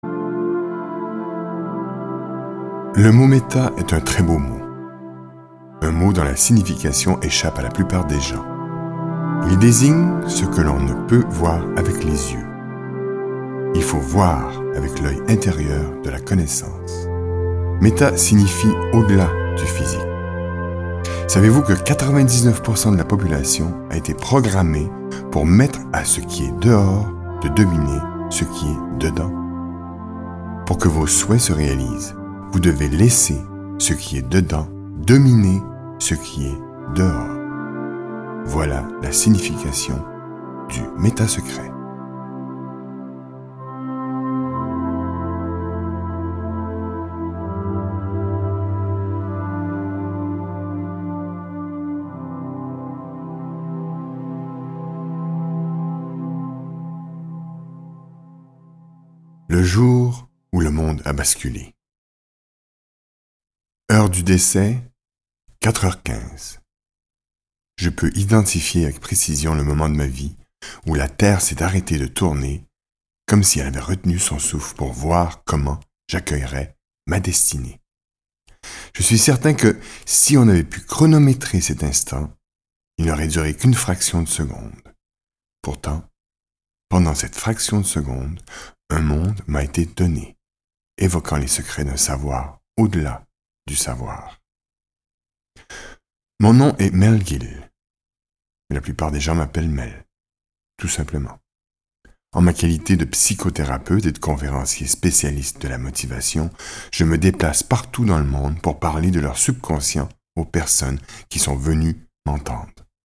Click for an excerpt - Le Méta-Secret de Mel Gill